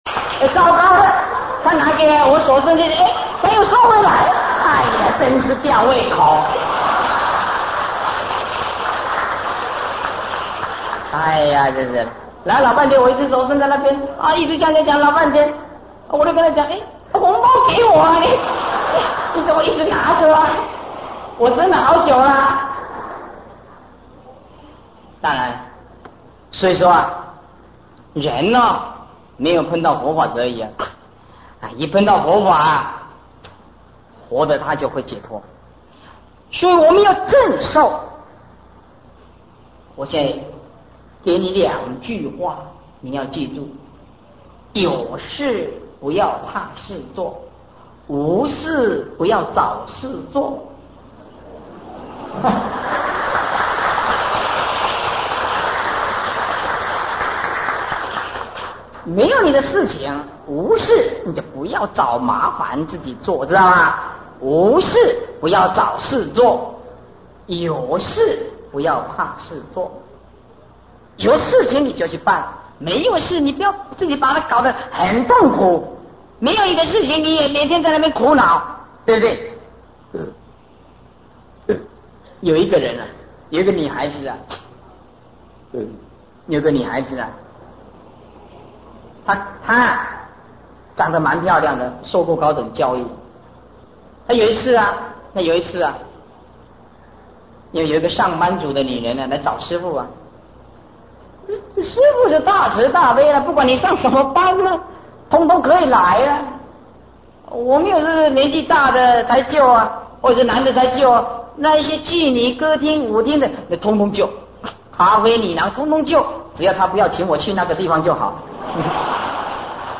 佛學講座